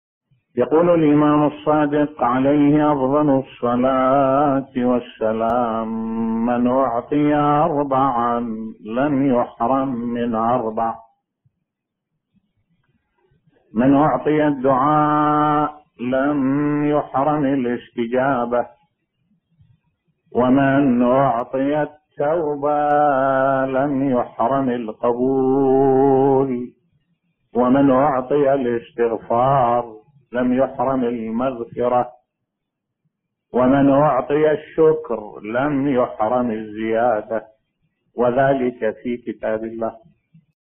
ملف صوتی حديث عن الإمام الصادق عليه السلام بصوت الشيخ الدكتور أحمد الوائلي